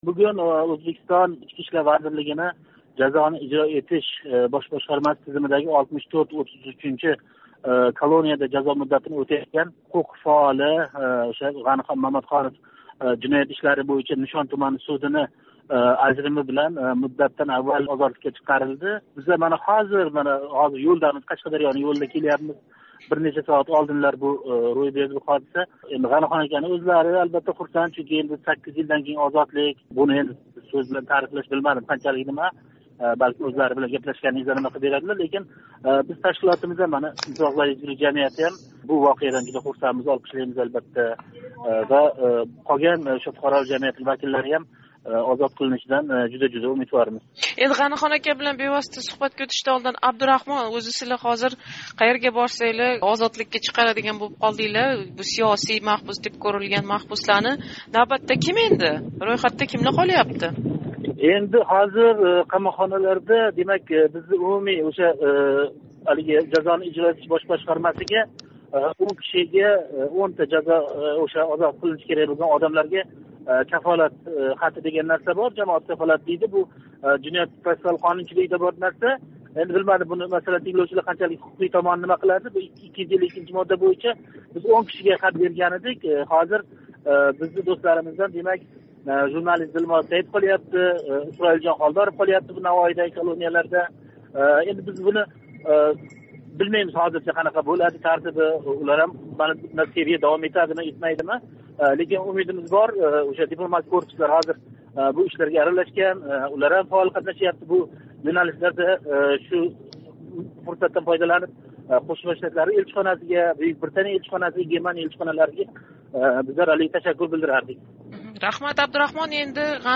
суҳбат